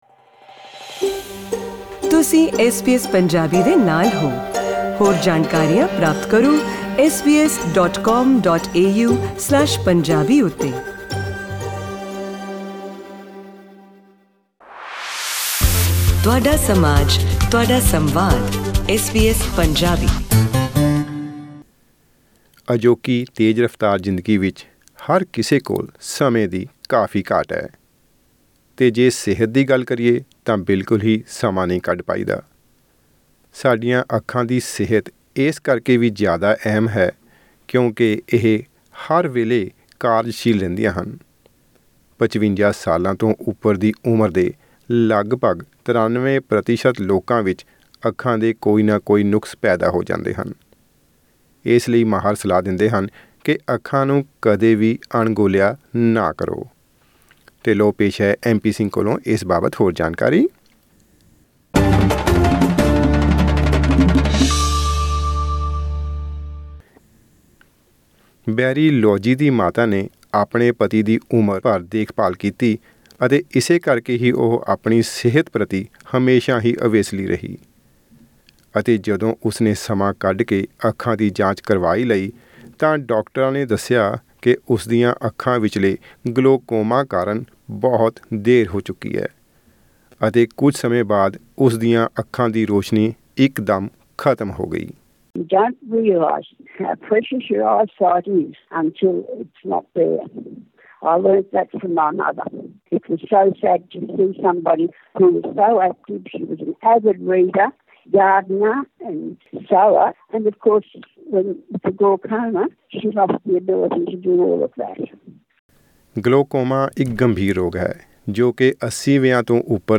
55 ਸਾਲਾਂ ਤੋਂ ਉਪਰ ਦੀ ਉਮਰ ਦੇ ਲਗਭਗ 93% ਲੋਕਾਂ ਵਿੱਚ ਅਕਸਰ ਅੱਖਾਂ ਦੇ ਕੋਈ ਨਾ ਕੋਈ ਨੁਕਸ ਪੈਦਾ ਹੋ ਜਾਂਦੇ ਹਨ। ਸਿਹਤ ਮਾਹਿਰ ਸਲਾਹ ਦਿੰਦੇ ਹਨ ਕਿ ਅੱਖਾਂ ਦੀ ਸਿਹਤ ਨੂੰ ਕਦੇ ਵੀ ਅਣਗੋਲਿਆ ਨਹੀਂ ਕਰਨਾ ਚਾਹੀਦਾ। ਪੇਸ਼ ਹੈ ਇਸ ਬਾਰੇ ਇਹ ਵਿਸ਼ੇਸ਼ ਆਡੀਓ ਰਿਪੋਰਟ।